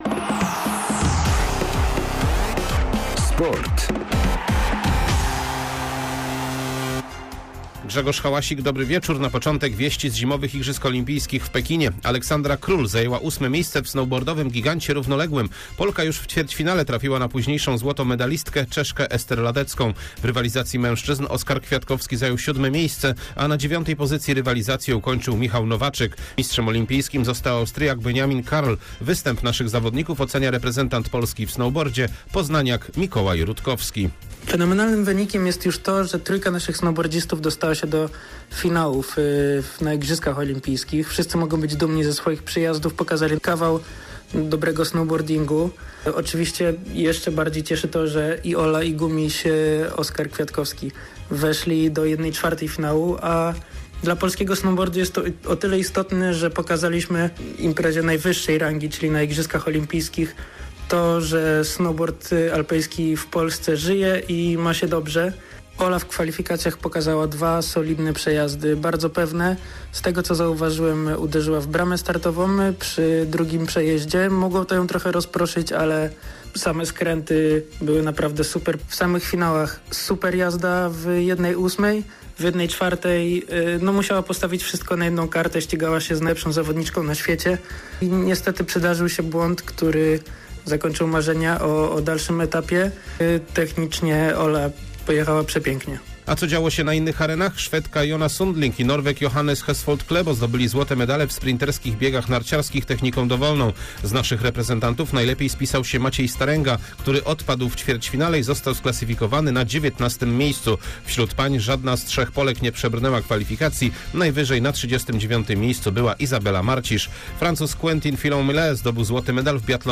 08.02.2022 SERWIS SPORTOWY GODZ. 19:05